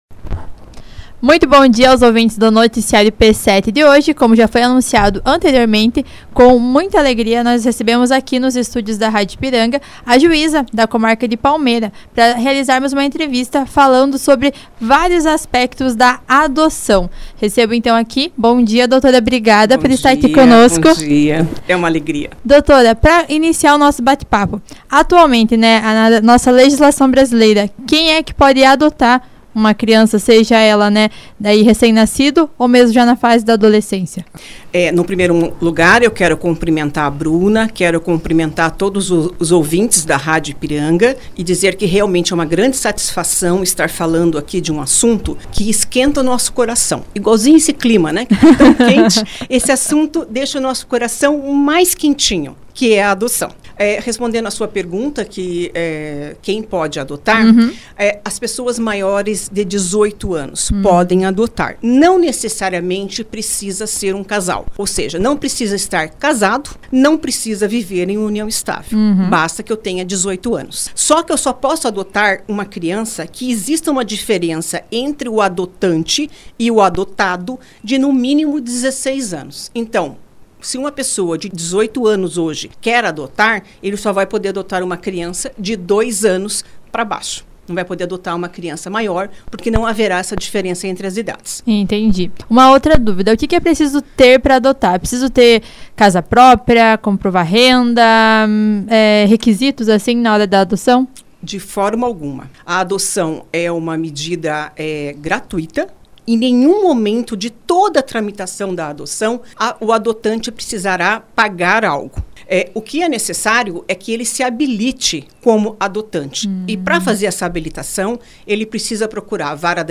Confira a entrevista completa com a Juíza da Comarca de Palmeira Dra. Claudia Sanine Ponich Bosco sobre o tema.
entrevista-adocao-para-noticiario-editada.mp3